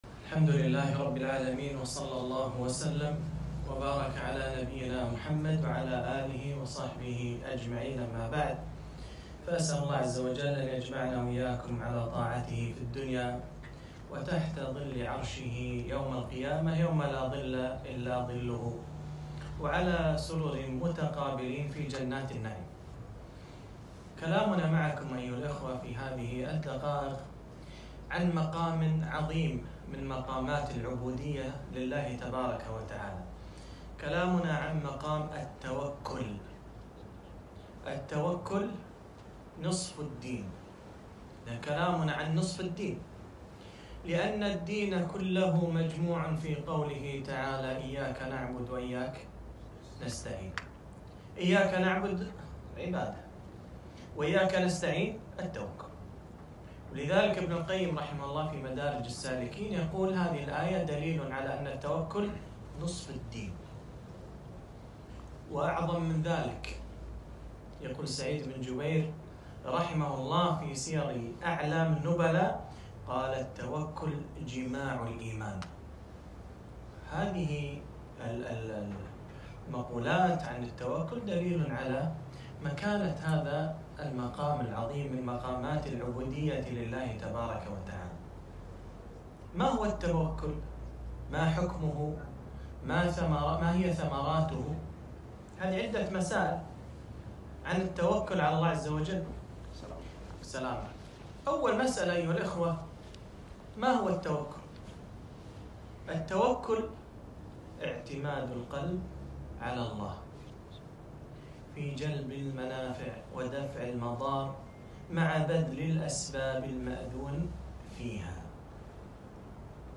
محاضرة - ومن يتوكل على الله فهو حسبه